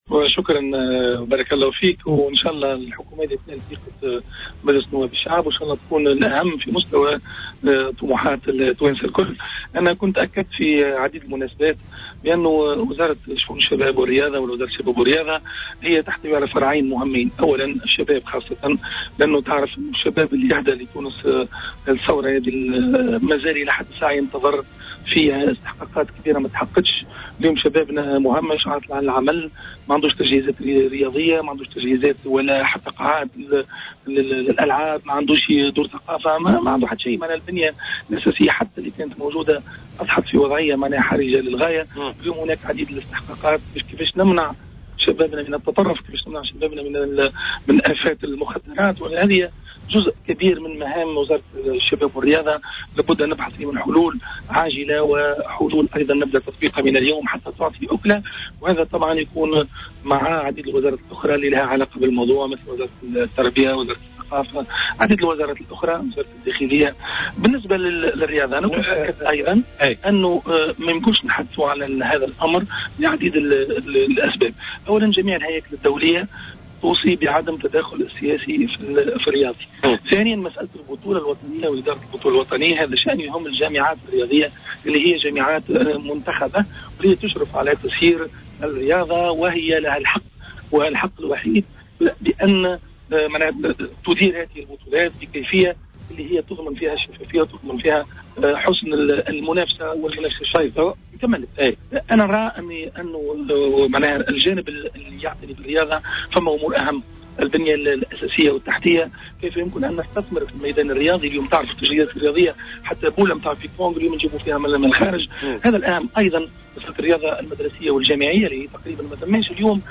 قال وزير للشباب والرياضة الجديد ماهر بن ضياء في تصريح للجوهرة أف أم اليوم الاربعاء إن مهمته الأساسية تتمثل في تحسين القطاع الرياضي بصفة عامة وتوفير المنشآت الرياضية وهي استحقاقات لم تتحقق للشباب التونسي، وليس منح ضربات الجزاء والانحياز لفريق معين.